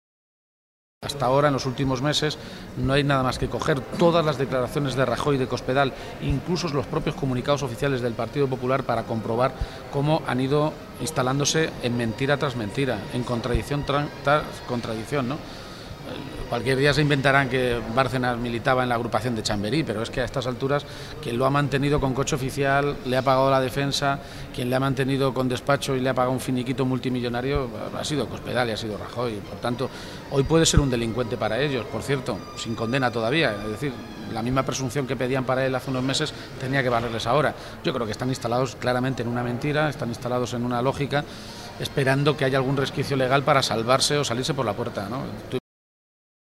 Page se pronunciaba de esta manera esta tarde, en San Lorenzo del Escorial, a preguntas de los medios de comunicación, minutos antes de intervenir en un curso sobre la Reforma Federal del Estado, en la que ha coincidido también con el líder de los socialistas extremeños, Guillermo Fernández Vara, y el vicesecretario general del PSOE de Andalucía, Mario Jiménez.
Cortes de audio de la rueda de prensa